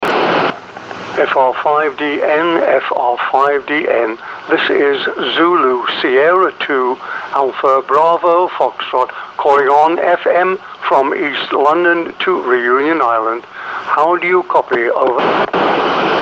FM.
Signals were usually good to excellent.